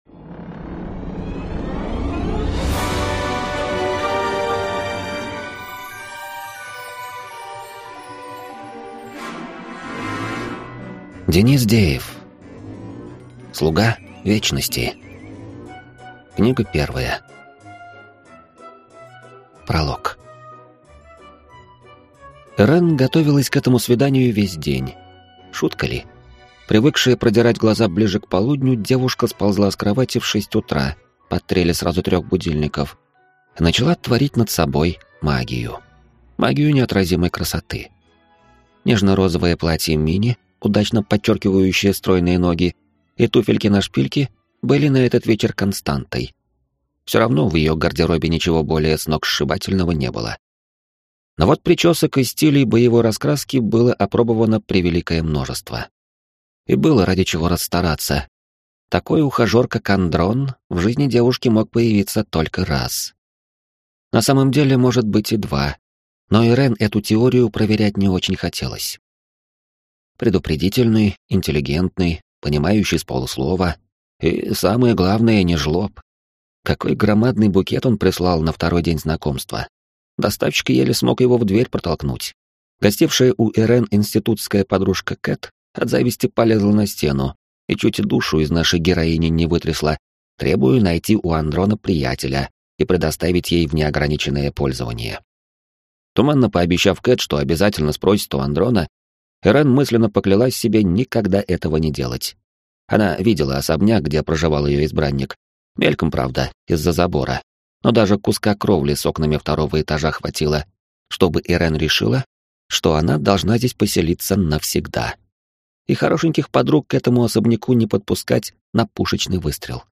Аудиокнига Слуга вечности. Книга 1 | Библиотека аудиокниг